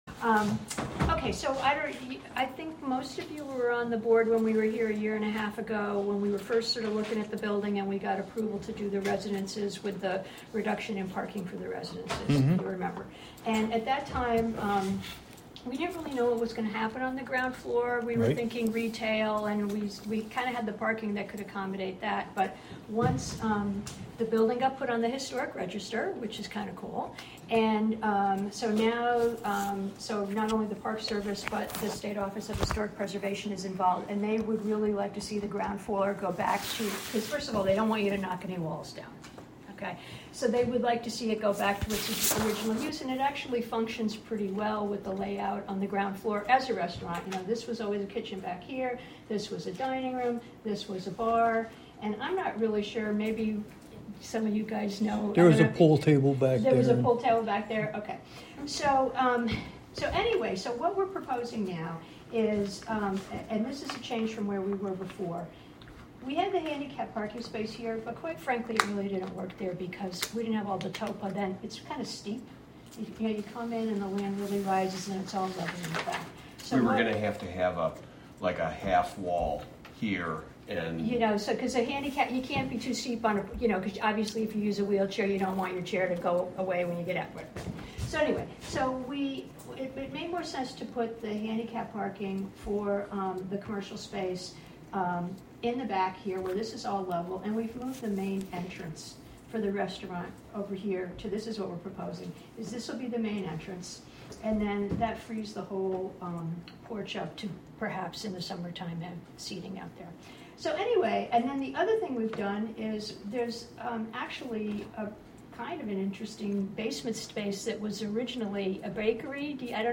Live from the Village of Philmont